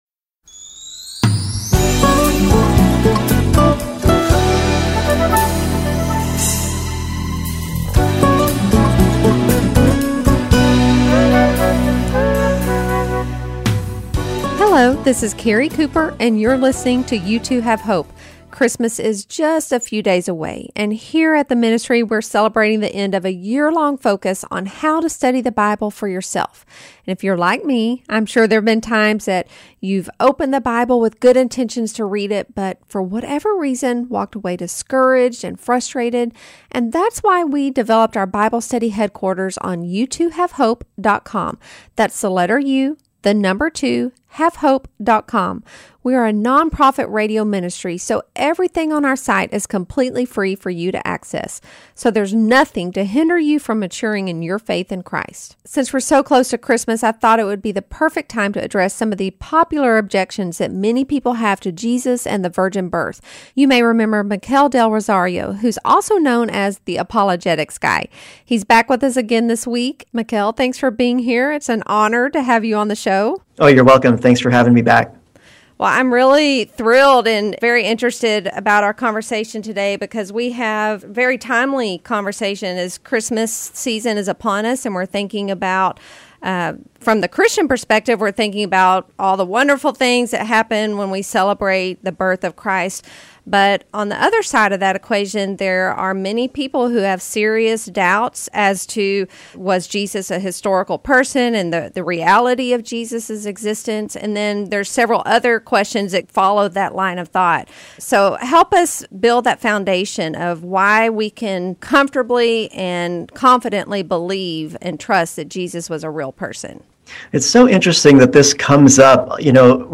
U2HaveHope airs on 91.3 FM KDKR (Dallas/Ft.Worth) and their network stations across the country. This show focuses on teaching listeners the "how to" of bible study as well as introducing basic apologetic topics.